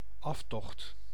Ääntäminen
France: IPA: [la ʁə.tʁɛt]